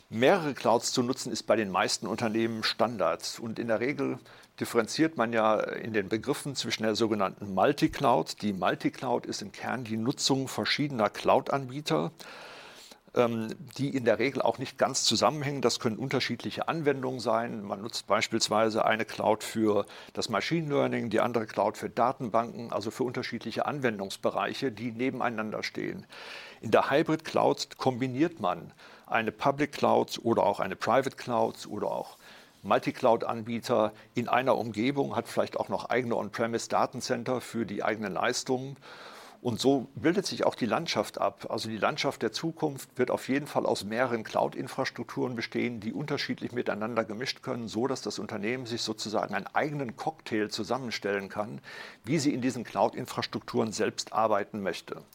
Mitschnitte der Pressekonferenz
bitkom-pressekonferenz-cloud-report-2025-audio-mitschnitt-cloud-infrastrukturen.mp3